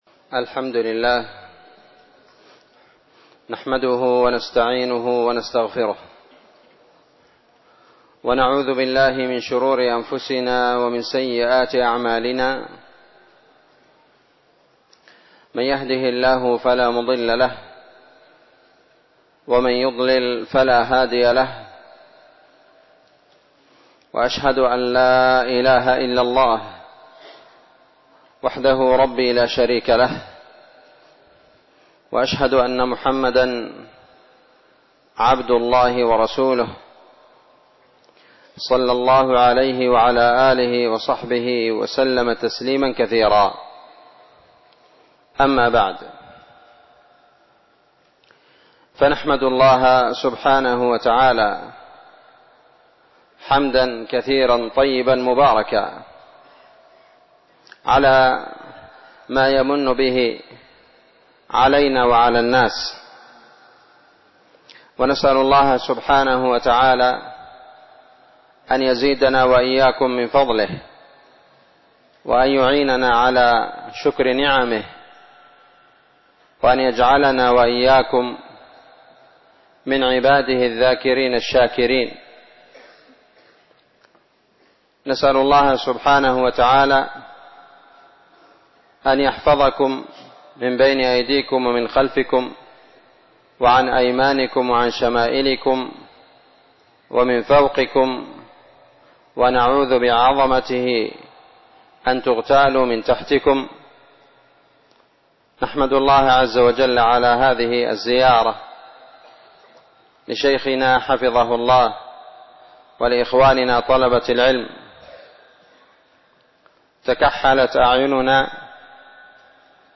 مسجد ابراهيم بشحوح